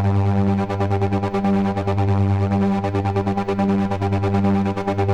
Index of /musicradar/dystopian-drone-samples/Tempo Loops/140bpm
DD_TempoDroneA_140-G.wav